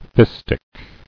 [fist·ic]